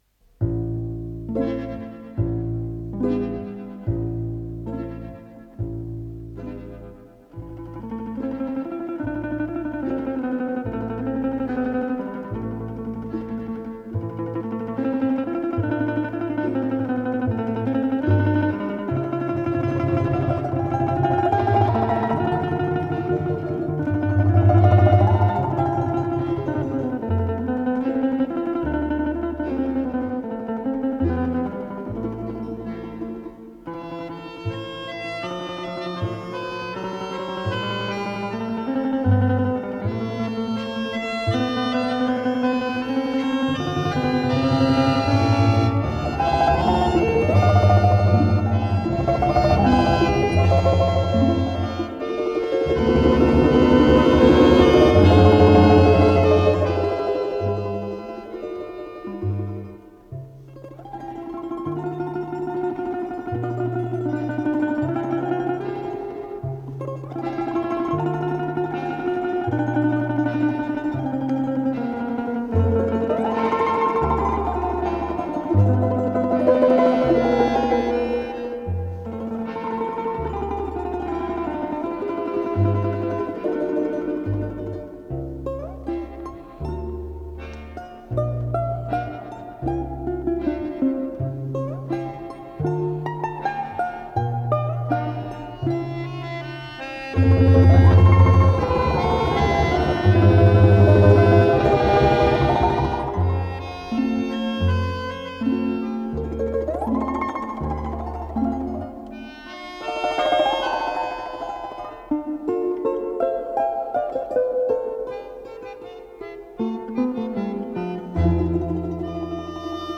с профессиональной магнитной ленты
домра
баян
балалайка-контрабас